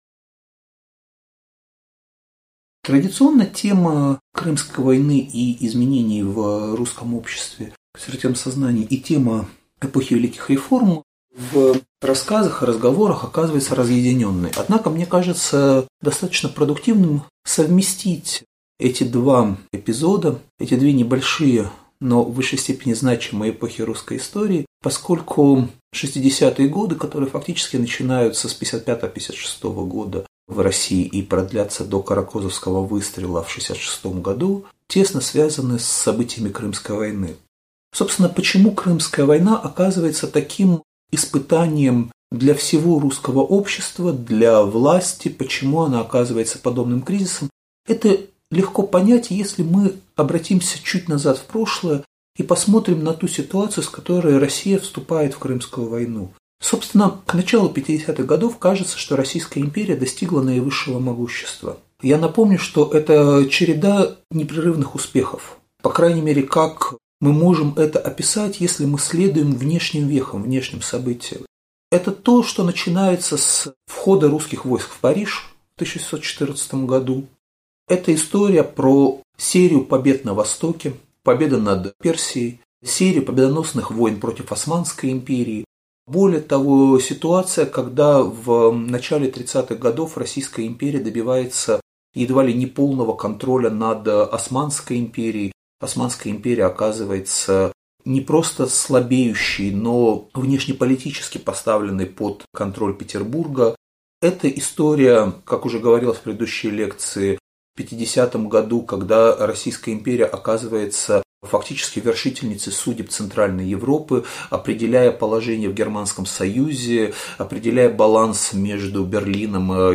Аудиокнига Лекция «Консерватизм в «эпоху великих реформ»» | Библиотека аудиокниг